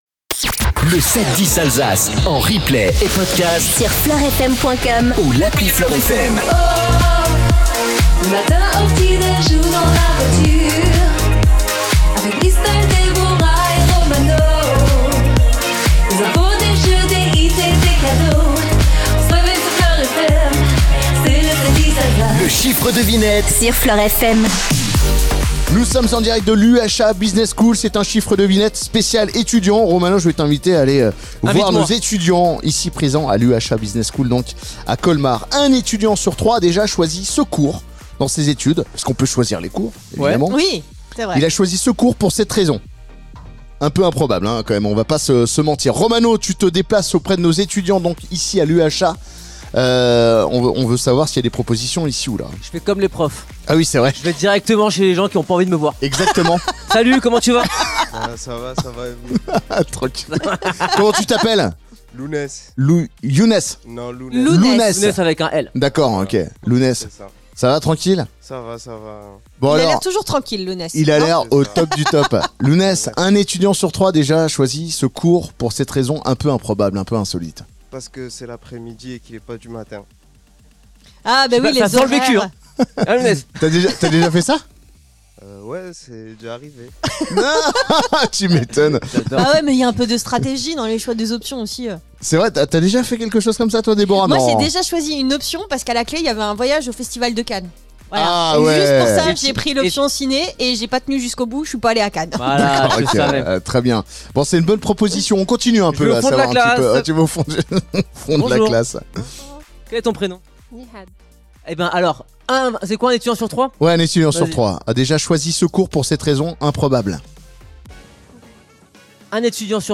Retrouvez les meilleurs moments du 7-10 Alsace en direct de l' UHA BUSINESS SCHOOL à Colmar